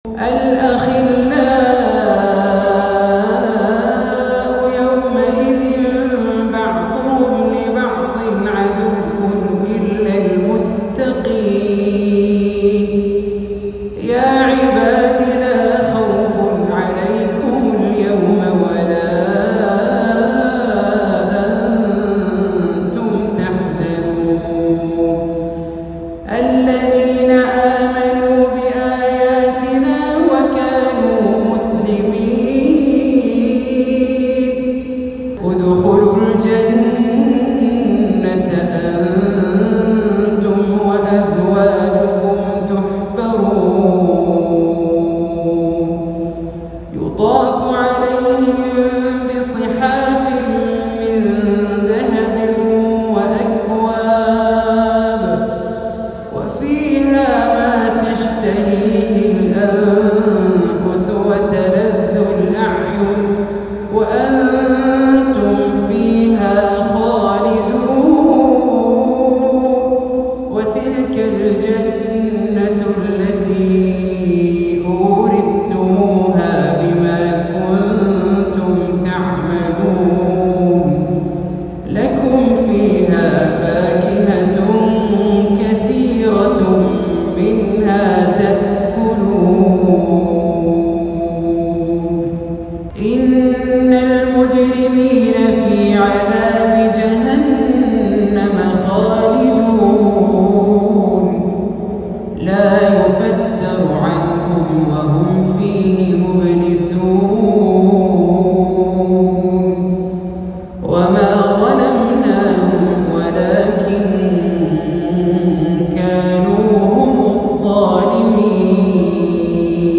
تلاوة مميزة وصوت ندي